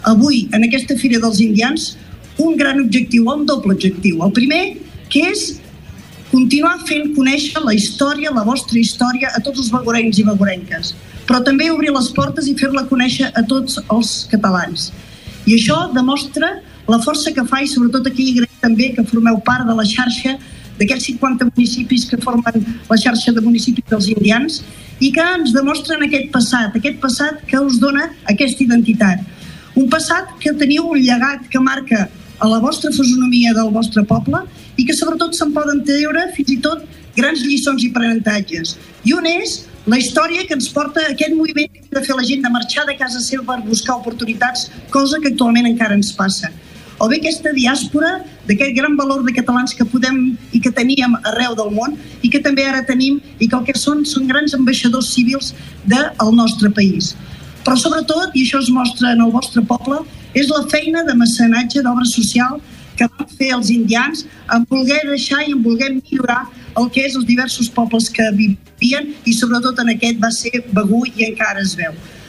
Anna Erra Solà ha destacat en el seu pregó que “Begur porta ja 18 anys engalanant el poble, convidant a la gent a conèixer Begur i els seus orígens i llegat.